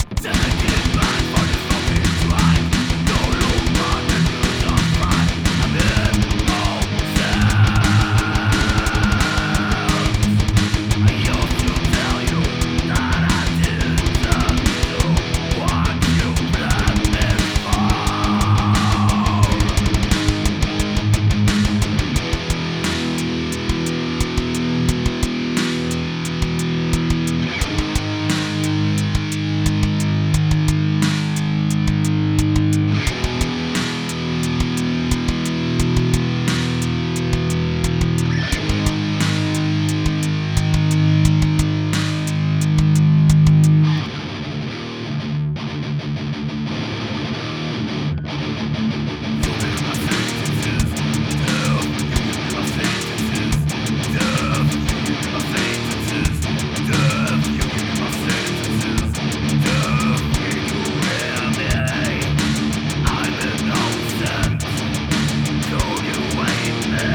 Ces sons sont bruts, j'ai juste nettoyé le trou au milieu.
On évite de trop toucher aux eqs (by by les problèmes de phases) et on se retrouve avec un son plus "gros" de fait car on a 2 guitares par côté.